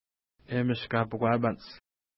Pronunciation: emis ka:pukwa:jpa:nts
Pronunciation